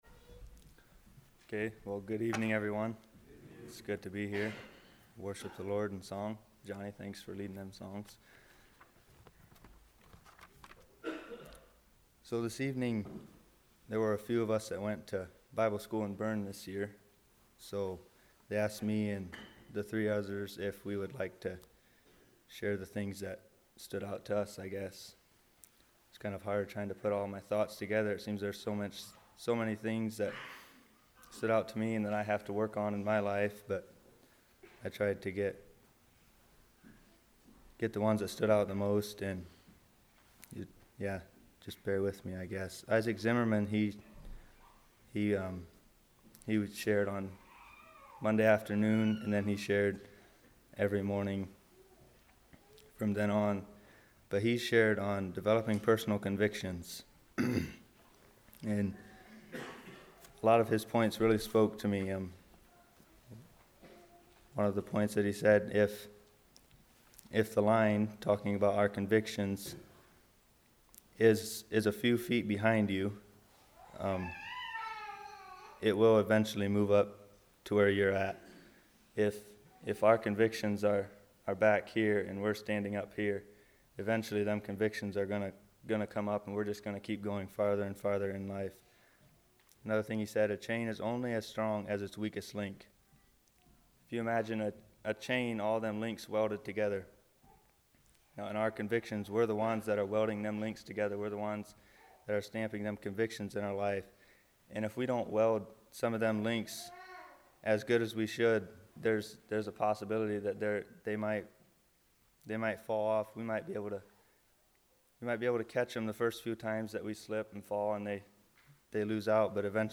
Bible School Testimonies